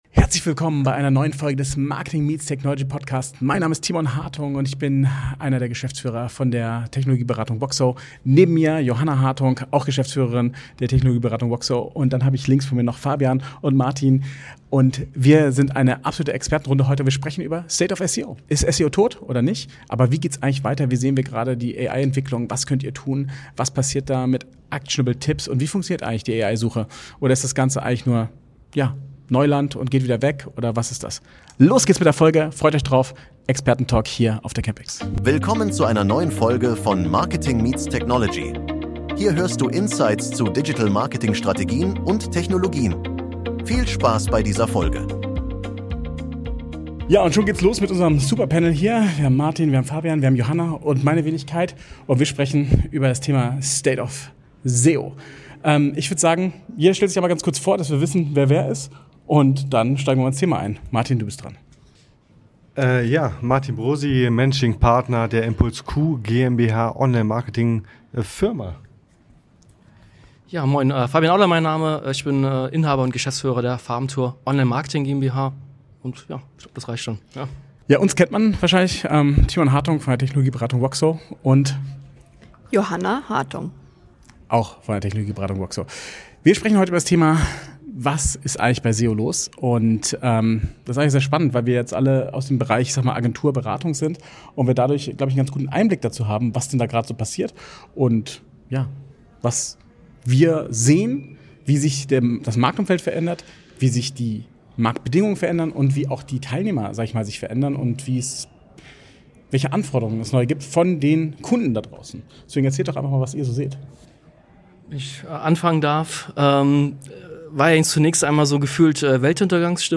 SEO 2025: Wie du jetzt sichtbar bleibst - Experten Panel Diskussion ~ Marketing Meets Technology: Digital Marketing Strategien | Innovative Technologien | SEO | AI Podcast